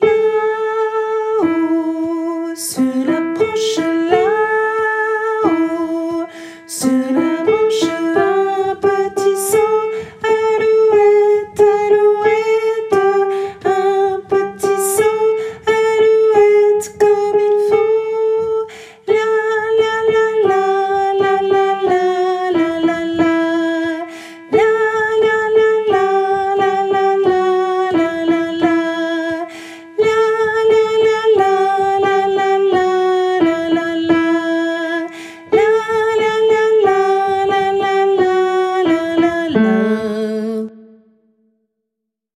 - Œuvre pour choeur à 4 voix mixtes (SATB)
- chanson populaire de Lorraine
MP3 versions chantées
Basse